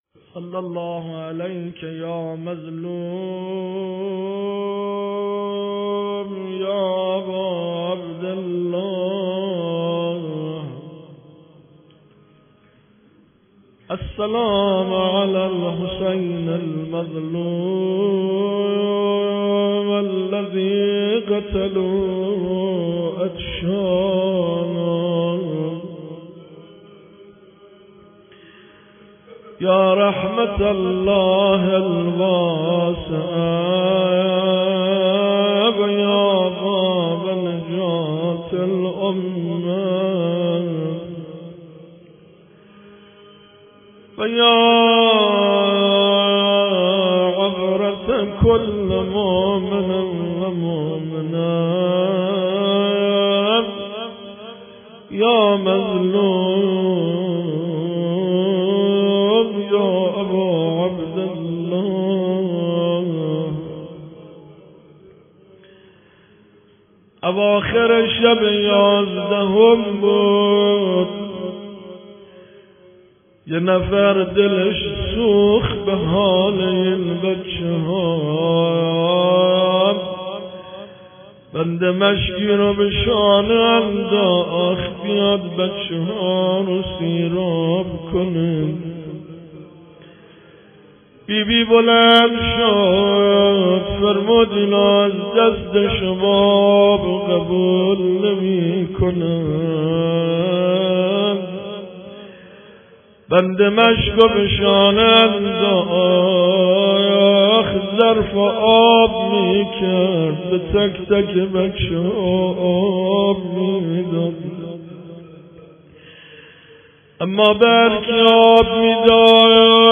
روضه شبهای رمضان ، روز یکم ، ۱۳۹۶/۳/۶
روضه ها